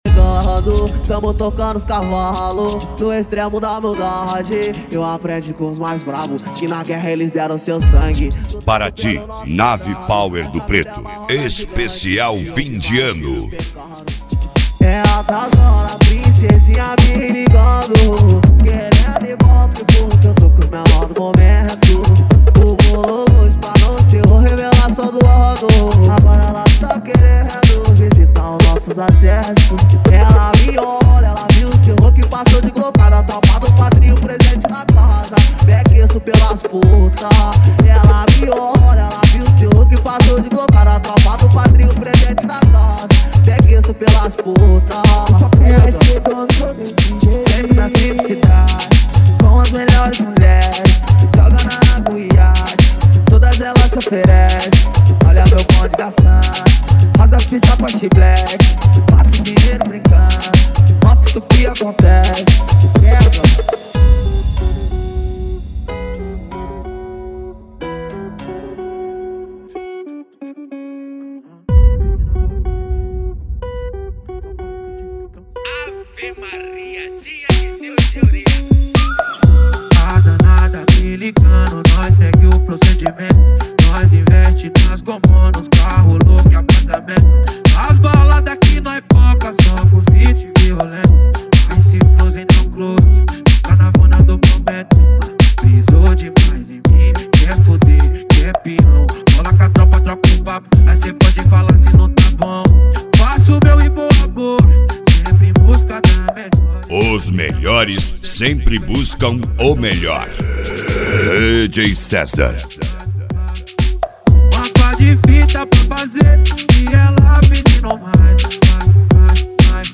Mega Funk
SERTANEJO